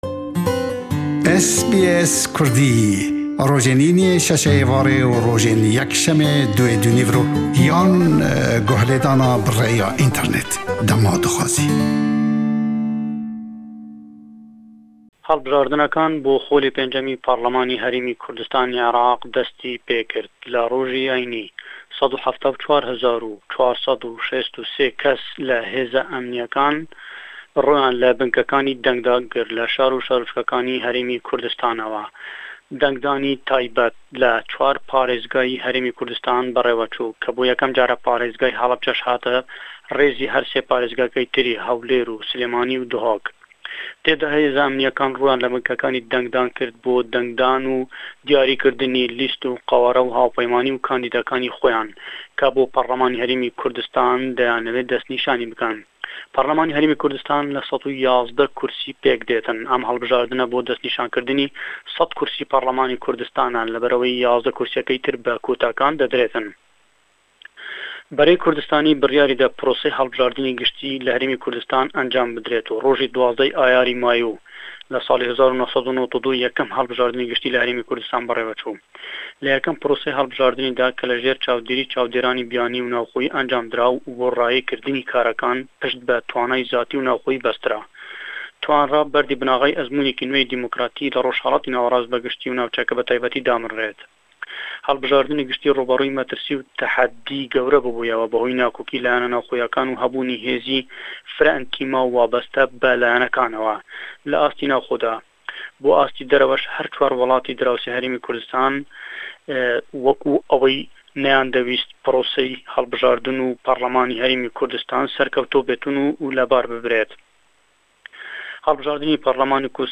ji Hewlêrê derbarê hilbijartinên gishtî li Herêma Kurdistanê ku îroj (30/09/2018) dest pê dikin diaxafe.